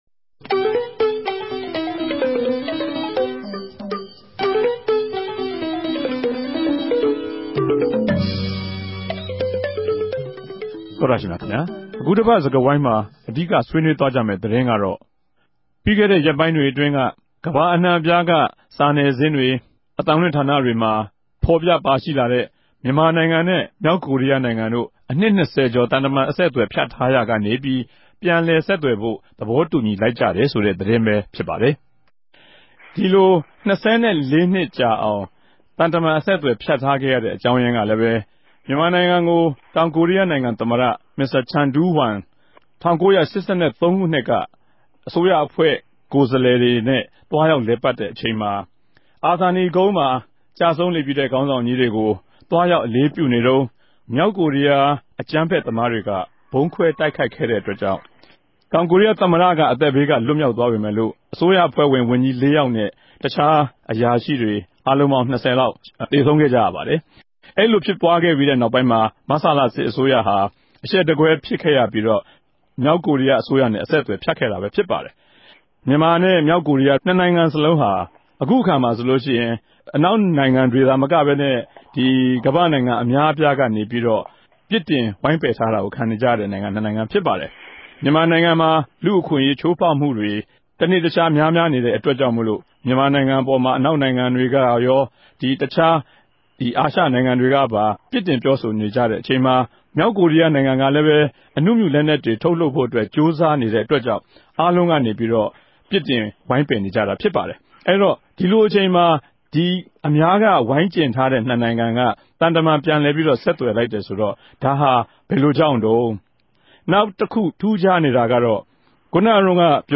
အခုတပတ် တနဂဿေိံြ ဆြေးေိံြးပြဲ စကားဝိုင်းမြာ ူမန်မာိံိုင်ငံက သံတမန် အဆက်ူဖတ်ထားတဲ့ႛ ေူမာက်ကိုရီးယားိံိုင်ငံနဲႛ ူပန်လည်ဆက်သြယ်ဖိုႛ သဘောတူညီမြ ရရြိလိုက်တဲ့ ကိင်္စနဲႛ အိံ္ဋိယ- ူမန်မာ ဆက်ဆံရေး တိုးပြားနေတဲႛ အခဵိန်မြာ ူမန်မာစစ်ဖက် ကိုယ်စားလြယ်အဖြဲႚ အ္ဋိံိယ ိံိုင်ငံကို သြားရောက်တဲ့အေုကာင်းတေကြို ဆြေးေိံြးသုံးသပ်ထားပၝတယ်၊၊